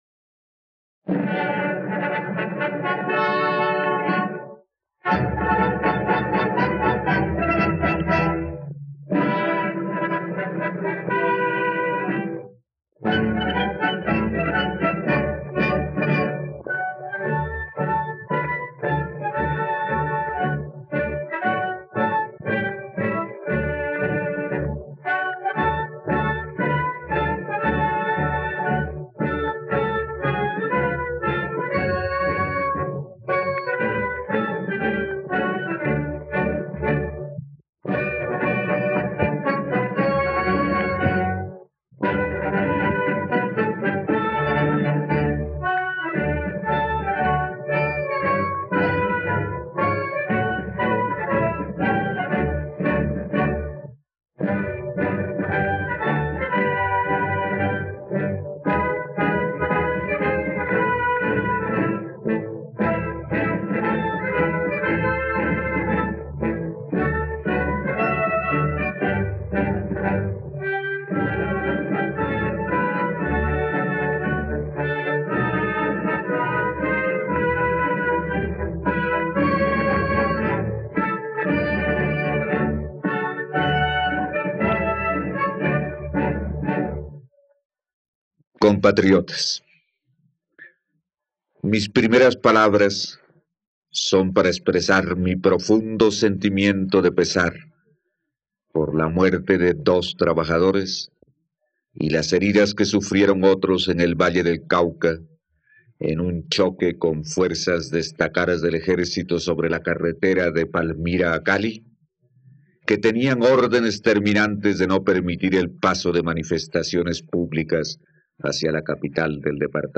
Discursos: Reacción a huelgas de trabajadores y sindicatos
..Escucha ahora el discurso de Alberto Lleras Camargo sobre las huelgas y sindicatos, el 15 de agosto de 1959, en la plataforma de streaming RTVCPlay.